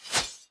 Sword Attack 1.ogg